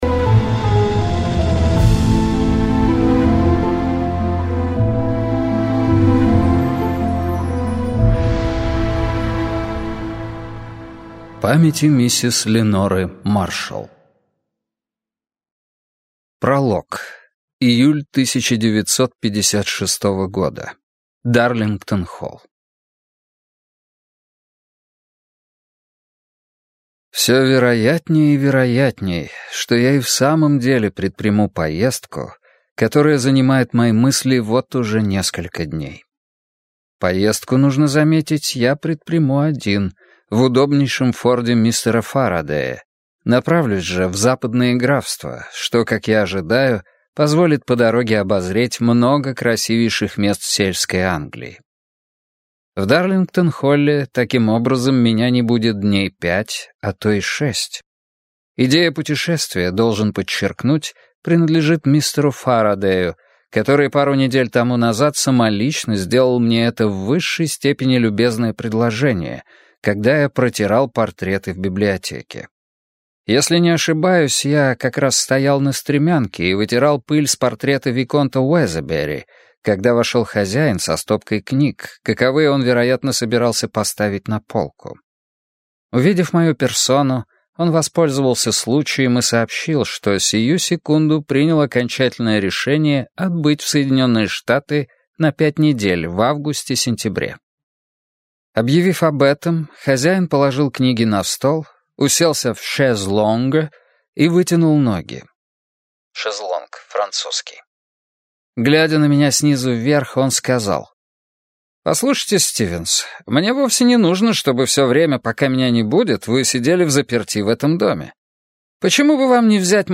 Аудиокнига Остаток дня - купить, скачать и слушать онлайн | КнигоПоиск